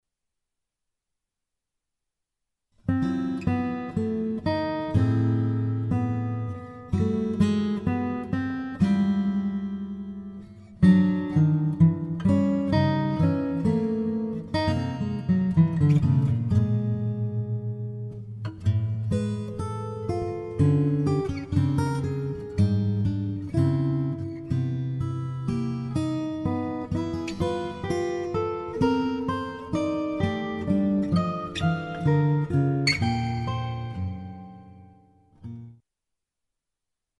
for 6-string guitar duet